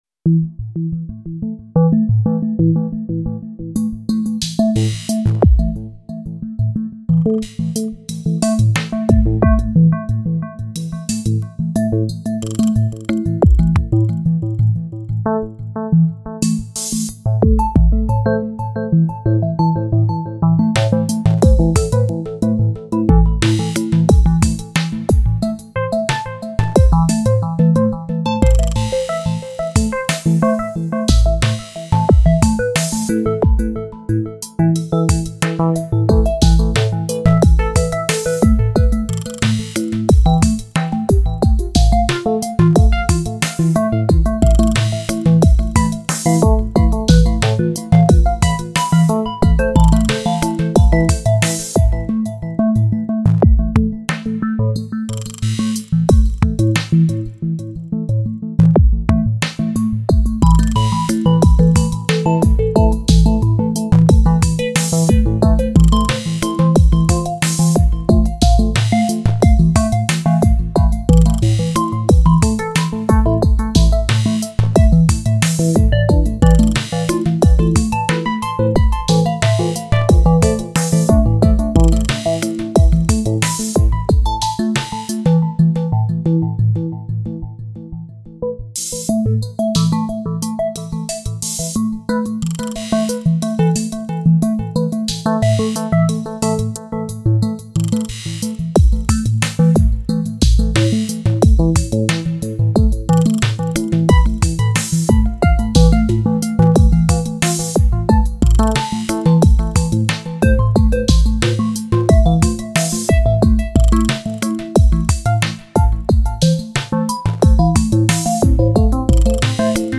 Digitone (DN+DT) only music
I definitely prefer its drums compared to MD.
Cool chill track
1 bar pattern, trig conditions, 2 tracks for drums with soundlocks, 1 for kick / snare, another one for hihats like. Live rec tweaking.
2 tracks with arp from default sound, random lfos on different fm parameters.
Not really a composition, A minor live playing.